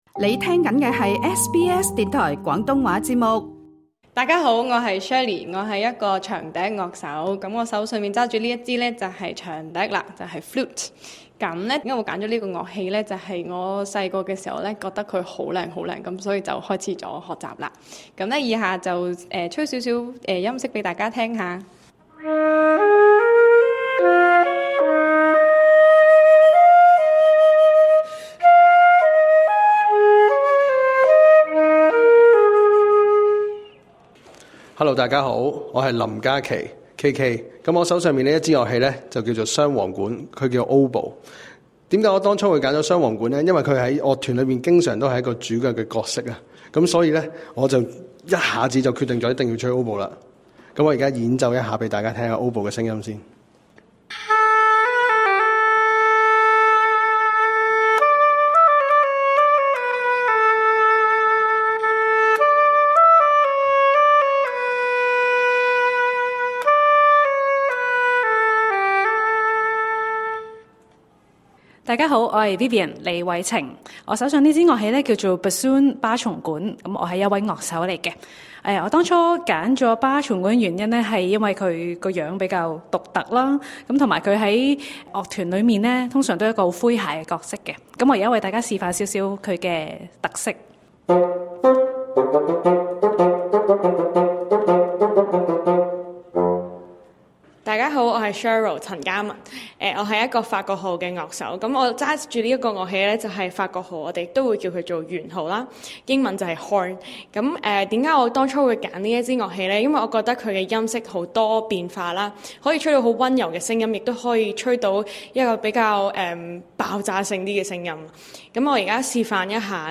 音樂會完結後和五位年輕人做了一個簡短的訪問，請點擊收聽。